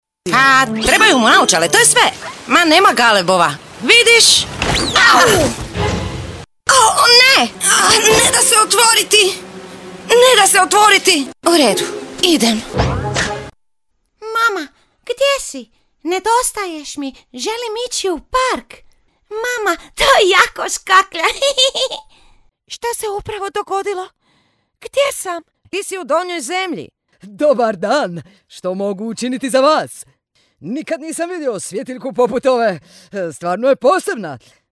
Kein Dialekt
Sprechprobe: Sonstiges (Muttersprache):